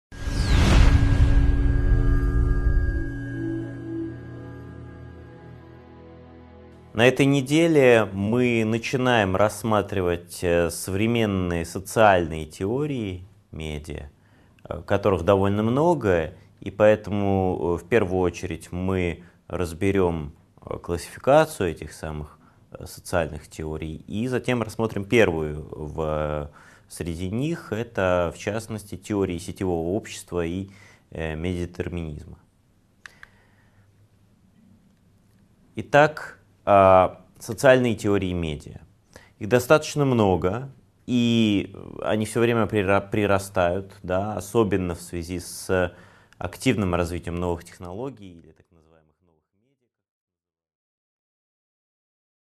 Аудиокнига 8.1 Идеи медиадетерминизма и сетевого общества: Карта социальных теорий медиа | Библиотека аудиокниг